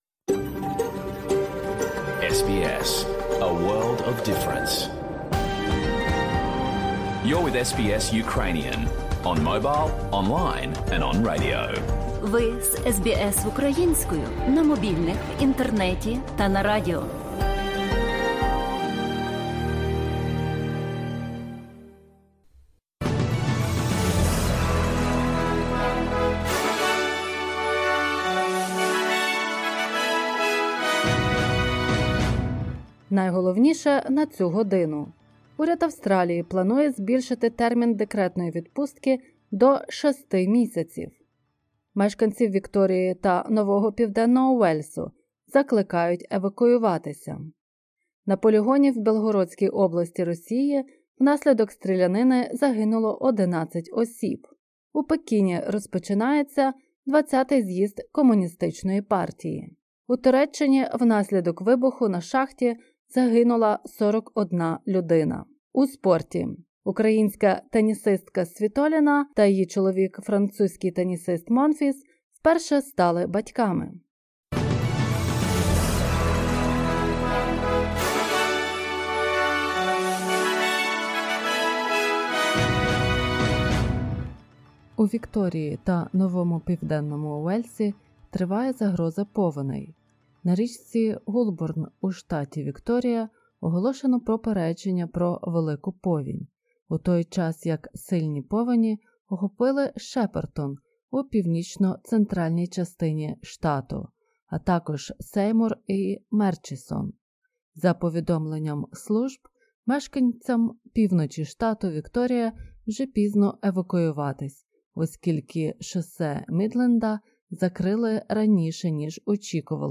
SBS News in Ukrainian – 16/10/2022